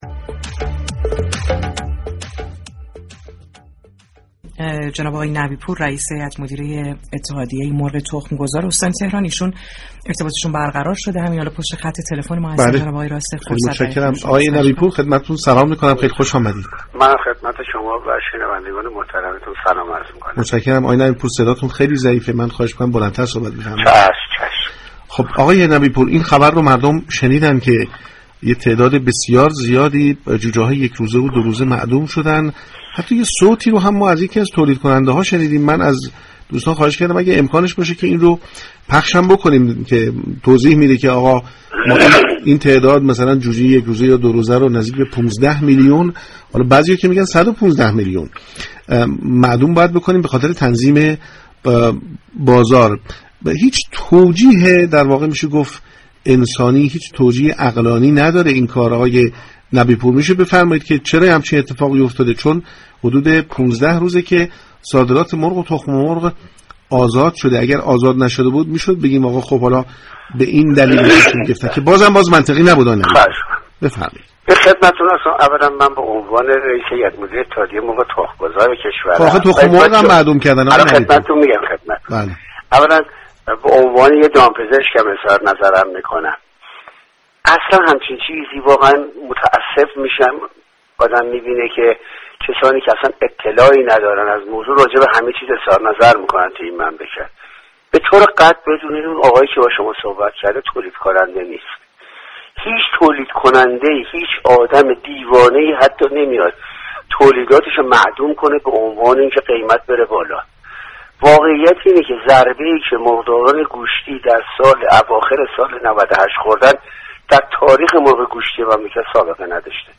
درباره معدوم كردن 15 میلیون جوجه با برنامه پارك شهر 30 فروردین ماه گفتگو كرد.